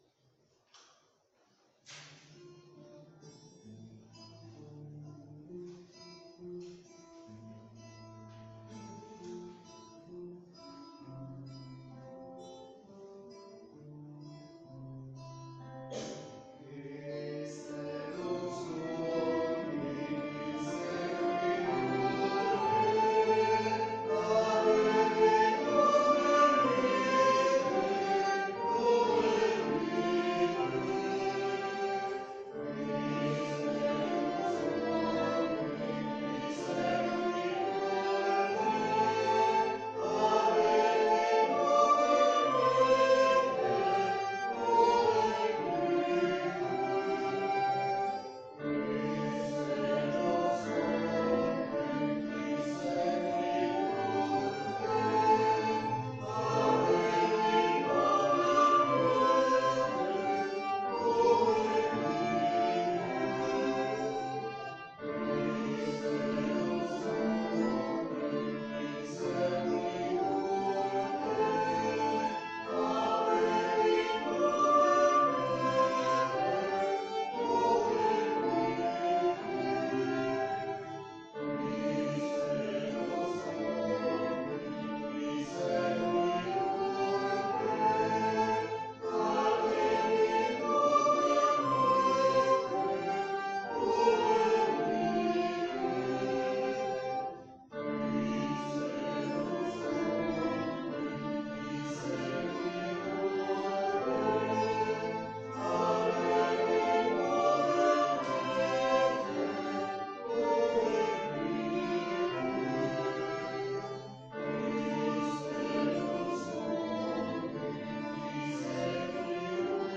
Pregària de Taizé
Ermita de Sant Simó - Diumenge 25 de gener de 2015